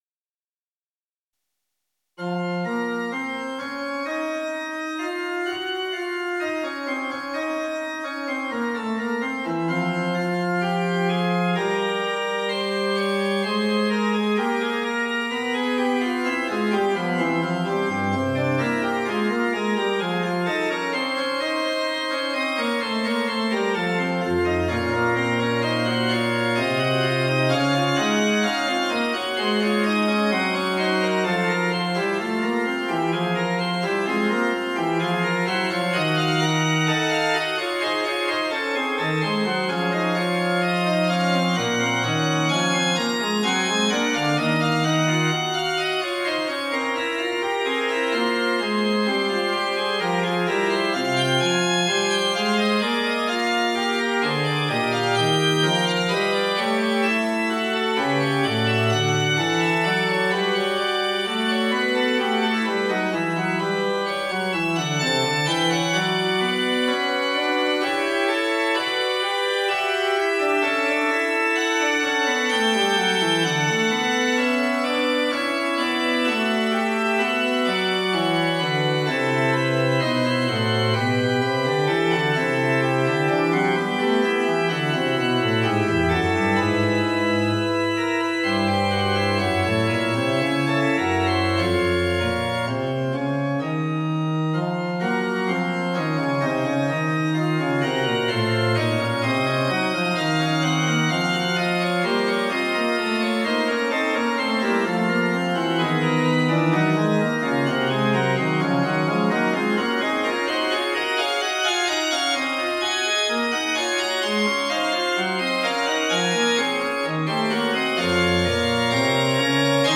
Fugue dans Bm.mp3
готично і божественно 39 це про музику